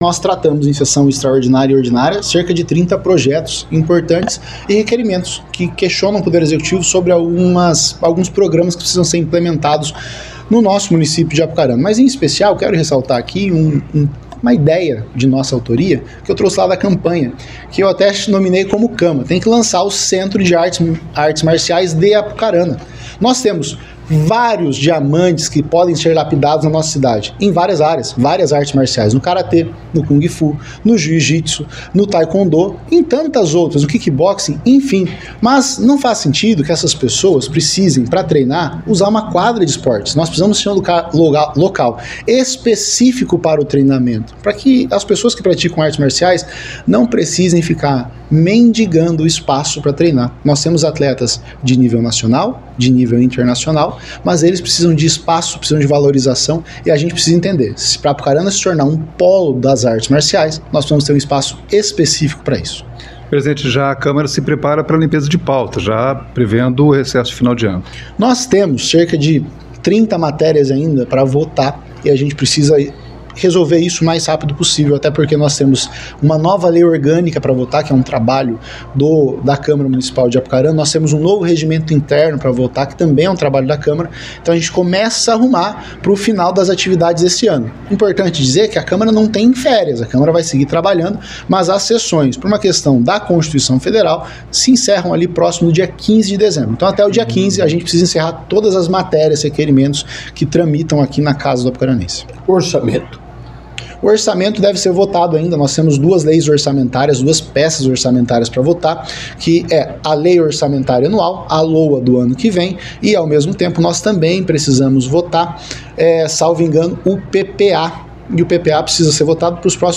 • Acompanhe as entrevistas realizadas com os vereadores após a sessão que teve a participação on-line de Odarlone Orente e as faltas justificadas, de Tiago Cordeiro e Guilherme Livoti.